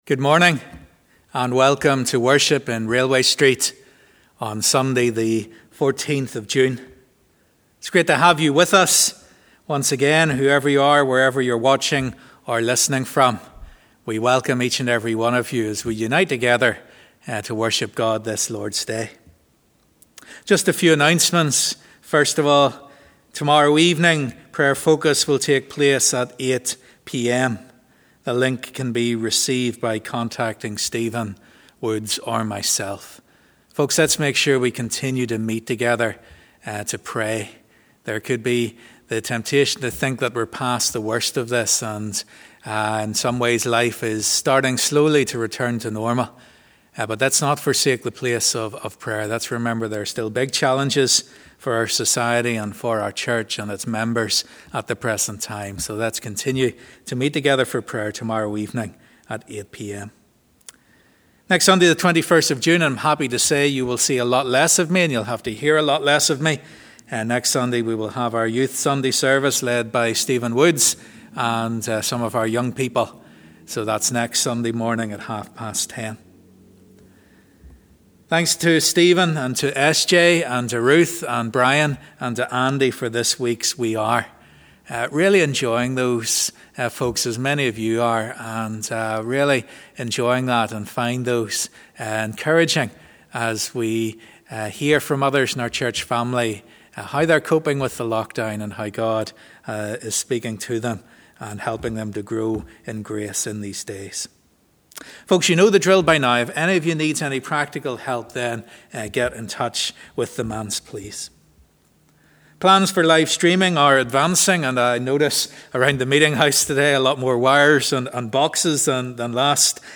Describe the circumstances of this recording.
As we come to worship we join in the words of 'Only A Holy God'. As we conclude let’s use the song 'Lord Reign In Me'.